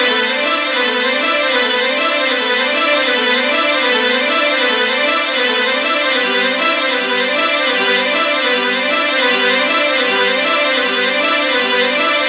Tornado Strings.wav